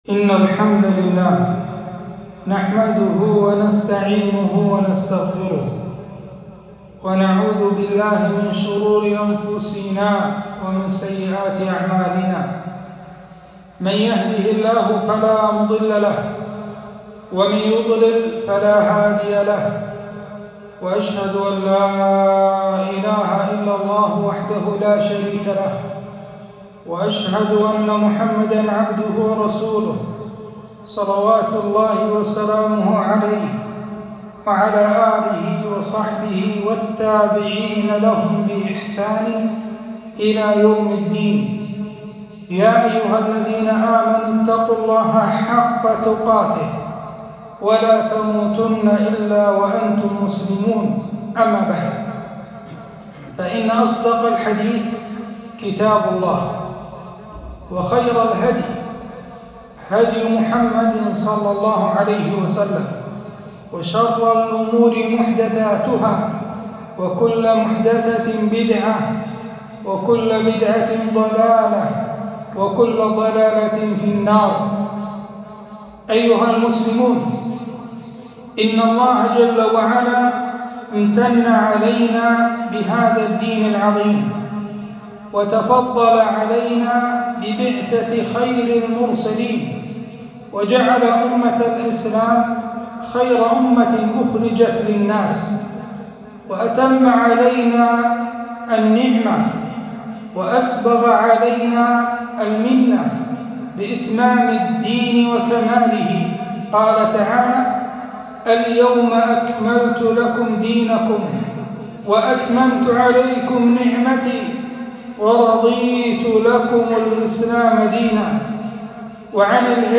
خطبة - السمع والطاعة لولاة الامر